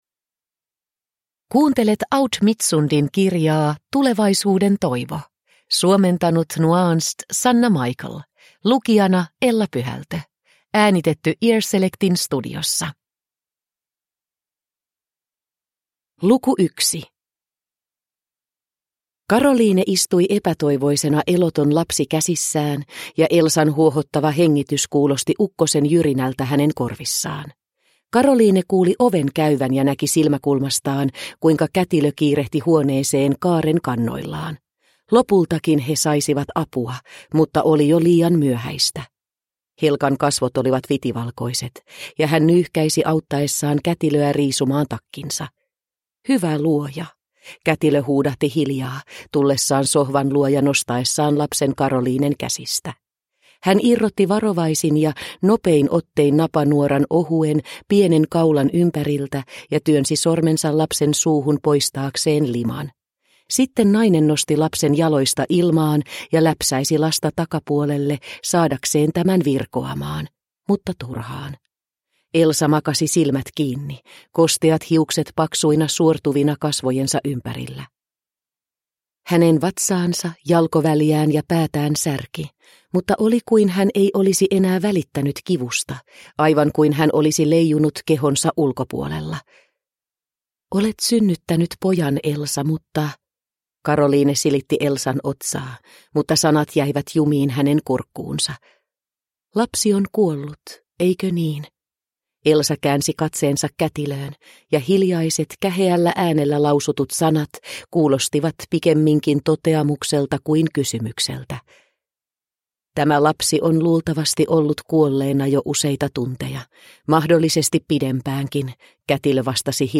Tulevaisuuden toivo (ljudbok) av Aud Midtsund